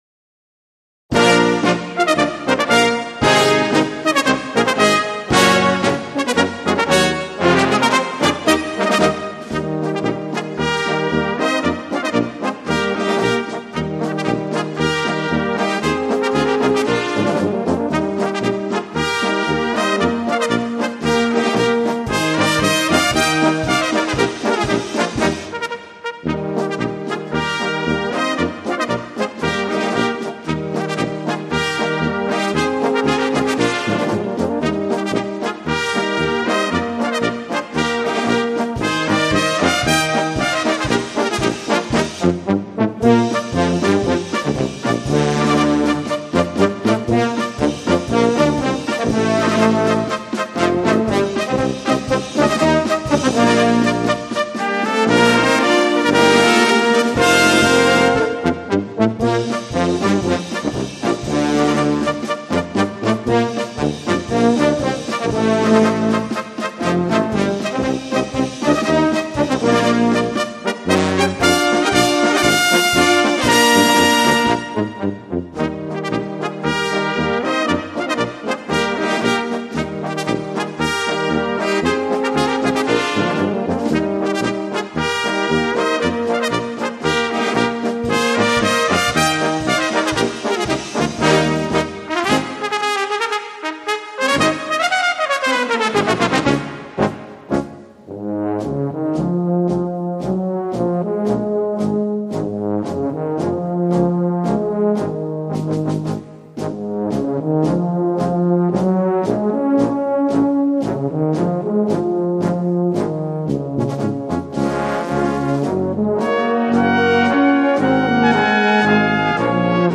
Gattung: Marsch für kleine Besetzung
Besetzung: Kleine Blasmusik-Besetzung